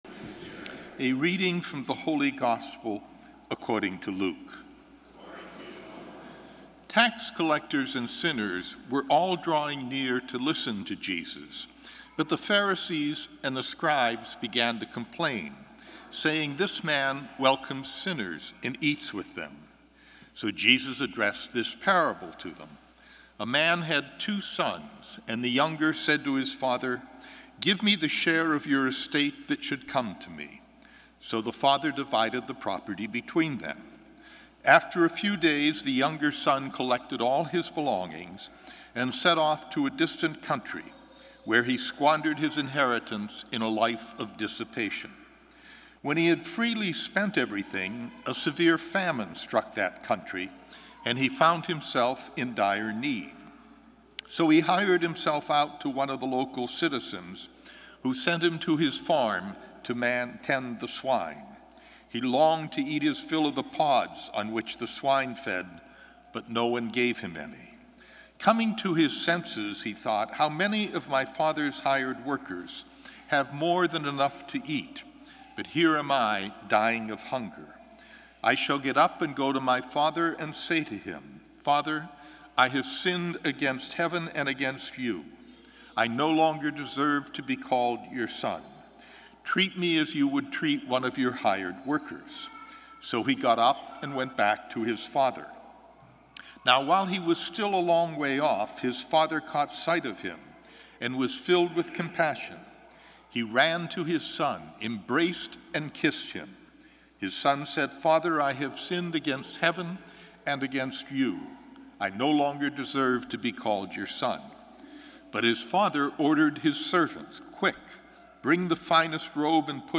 Gospel & Homily March 6, 2016